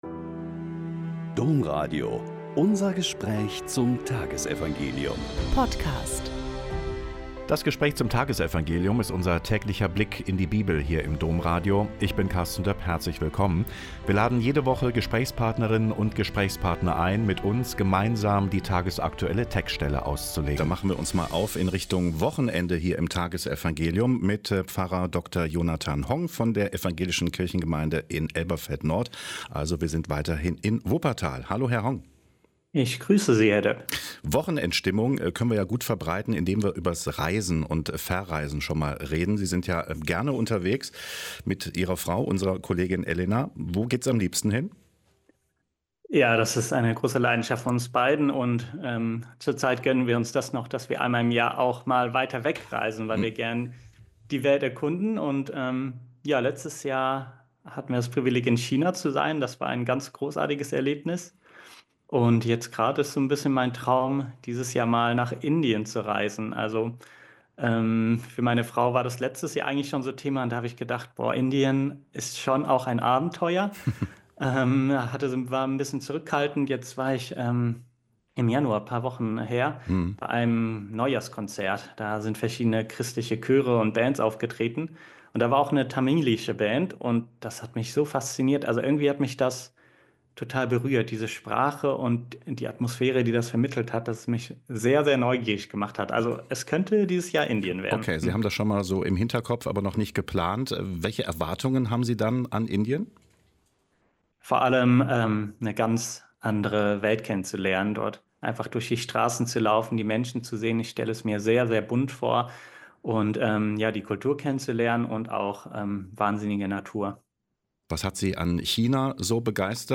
Mk 4,26-34 - Gespräch